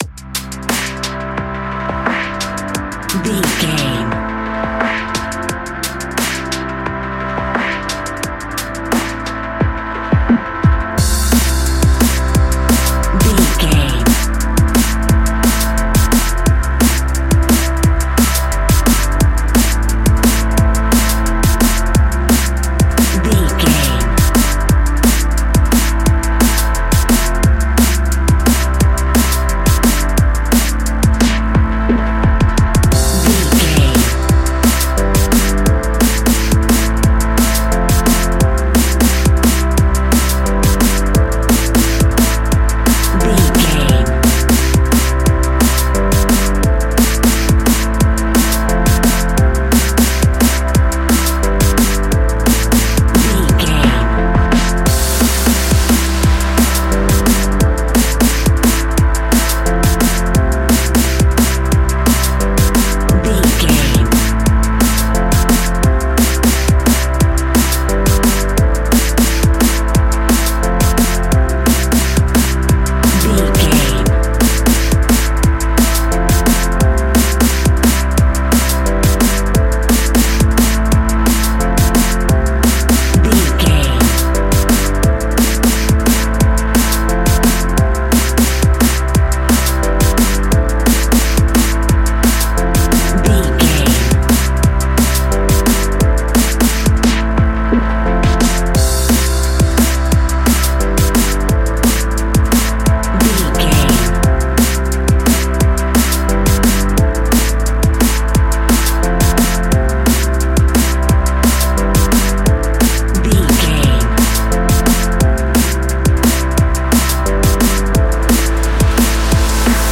Ionian/Major
Fast
uplifting
lively
hypnotic
industrial
drum machine
synthesiser
electronic
sub bass
synth leads
synth bass